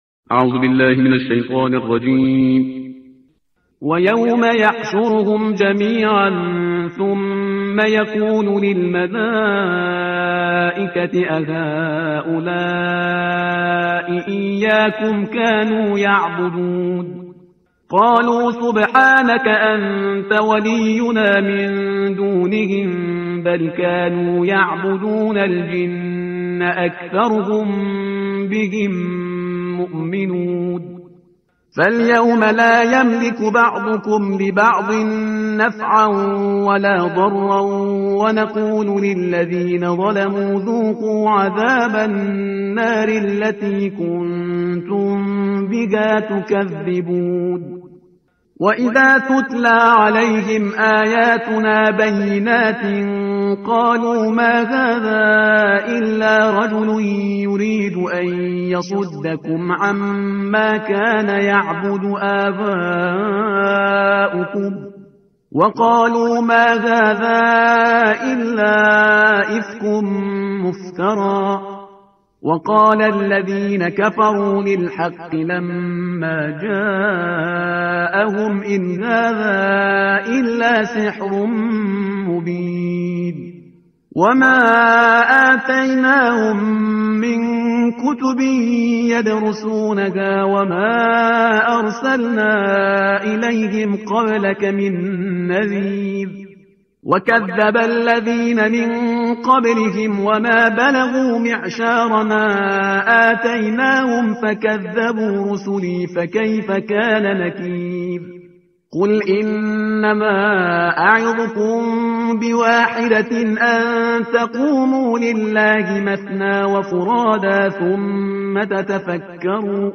ترتیل صفحه 433 قرآن با صدای شهریار پرهیزگار